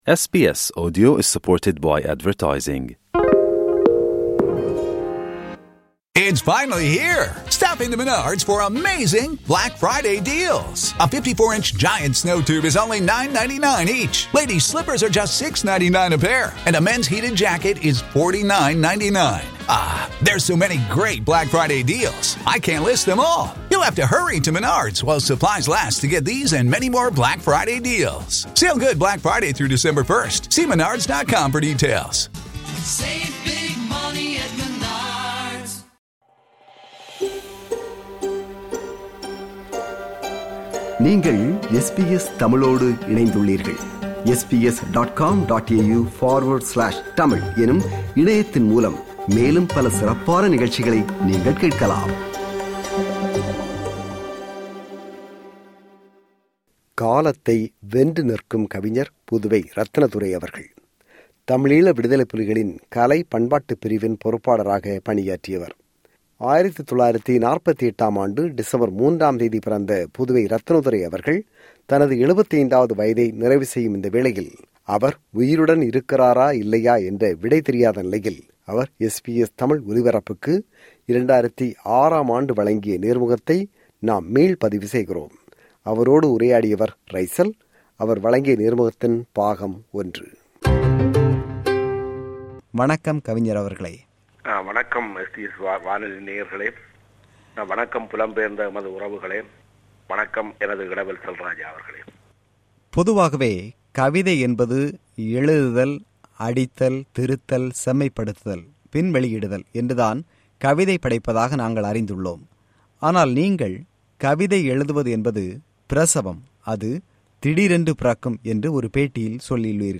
அவர் உயிருடன் இருக்கின்றாரா இல்லையா என்ற விடை தெரியாத நிலையில், டிசம்பர் 3 (1948) பிறந்த புதுவை இரத்தினதுரை அவர்கள் தனது 75 ஆவது வயதை நிறைவு செய்யும் இவ்வேளையில் அவர் SBS தமிழ் ஒலிபரப்புக்கு 2006 ஆம் ஆண்டு வழங்கிய நேர்முகத்தை மீள் பதிவு செய்கிறோம்.
நேர்முகம் – பாகம் 1.